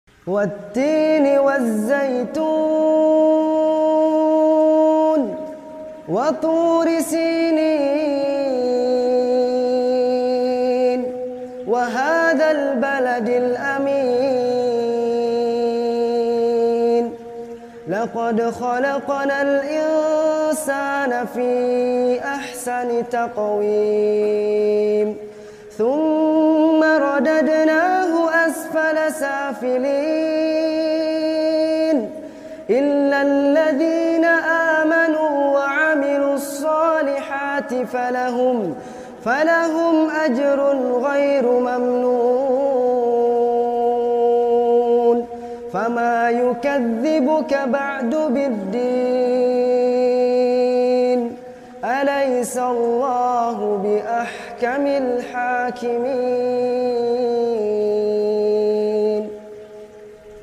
• Arabic Recitation: Perfect your pronunciation.
Reciter: Salim Bahanan